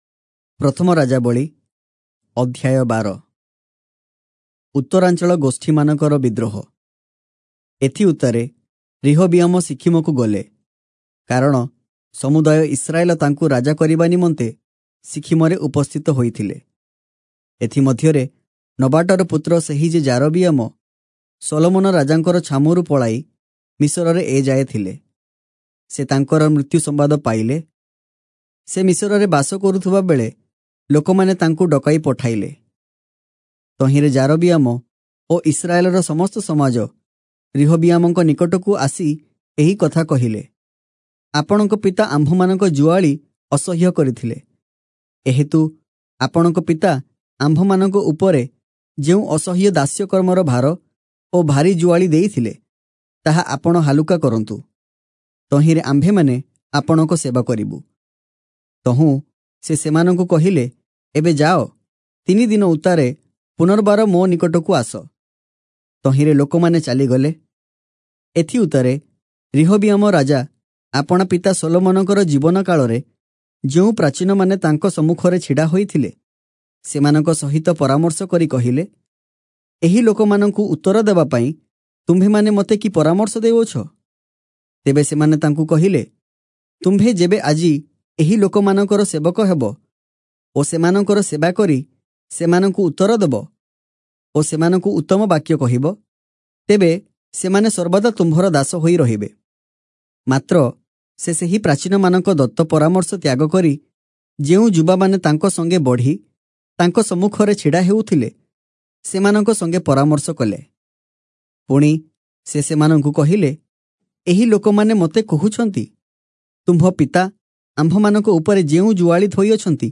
Oriya Audio Bible - 1-Kings 6 in Irvor bible version